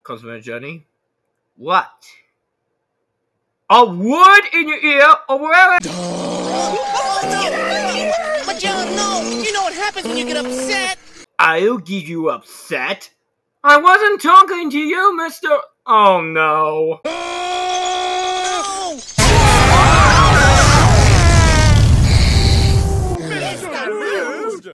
Mr Rude's Sneeze Fart Explosion Botón de Sonido
Fart Soundboard2 views